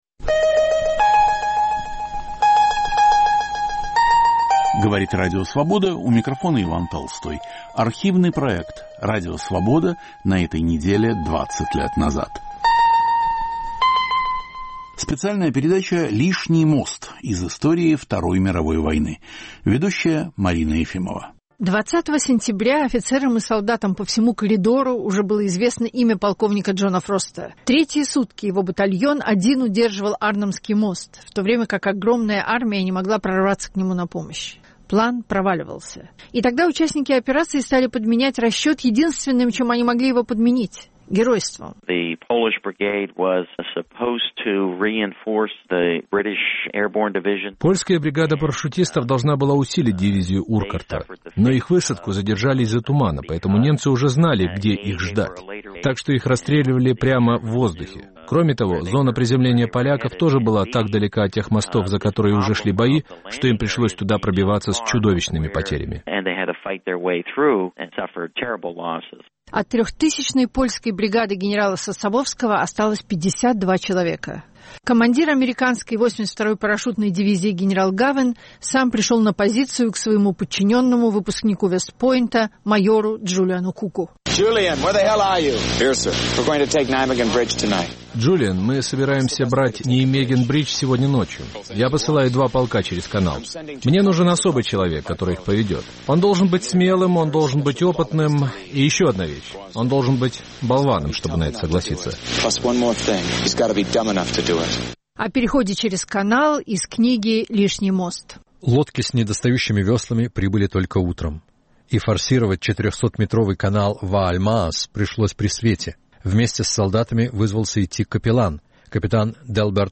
О видах насилия. Эфир 9 октября 2004.